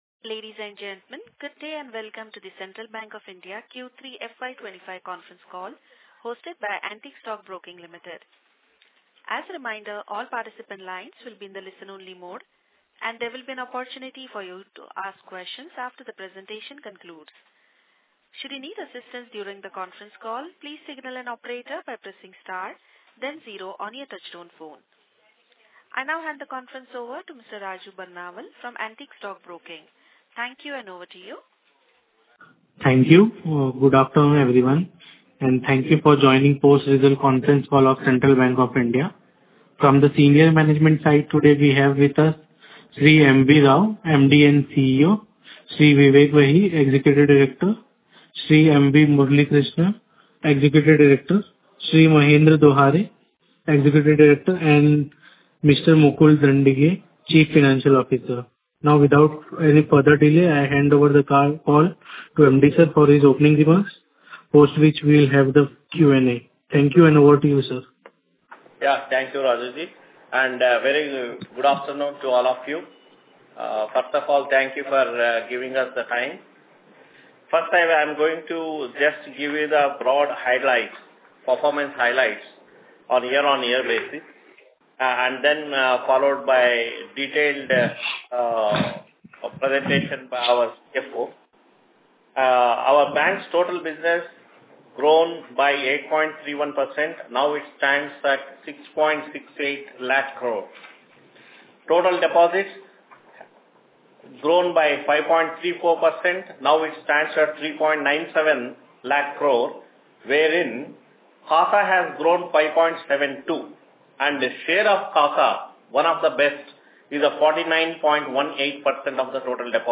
Conference Call with Analysts | Central Bank of India